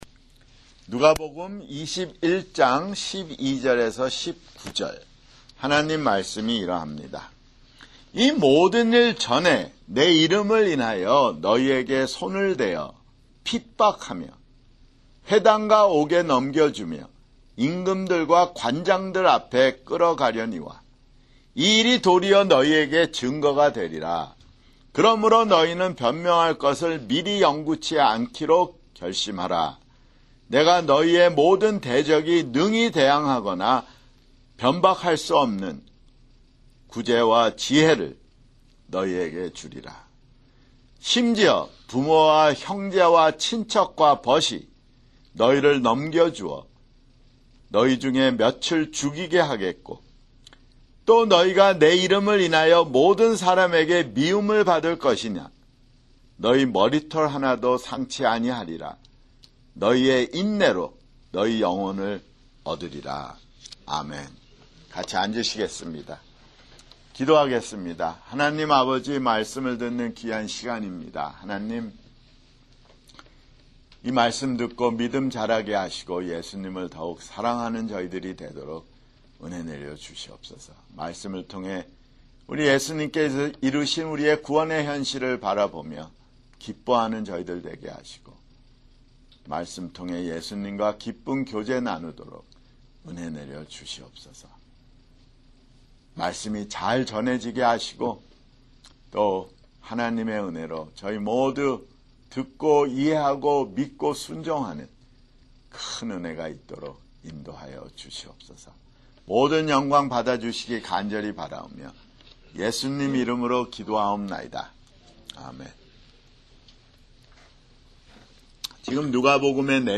[주일설교] 누가복음 (141)